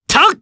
Clause-Vox_Attack2_jp.wav